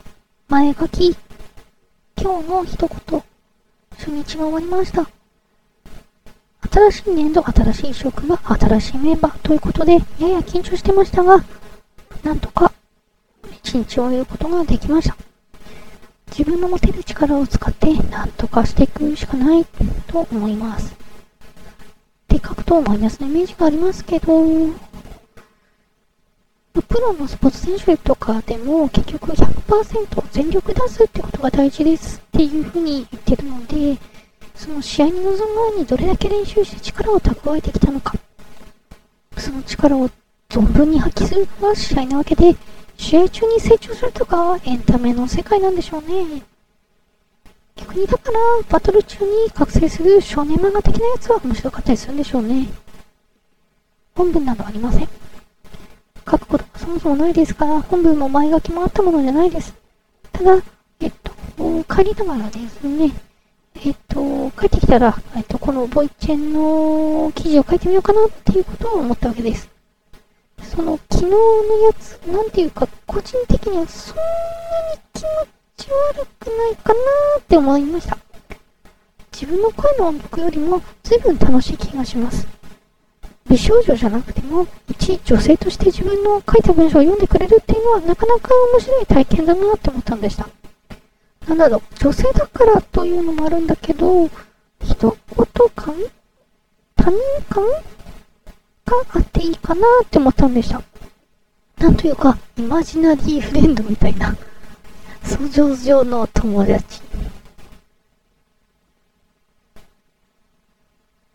美少女じゃなくても、いち女性として自分の書いた文章を読んでくれるってのは中々面白い体験だなと思ったのだ。
夜だからということで、小声でしゃべってると、Wav編集しても、雑音の処理が非常に難しくなる。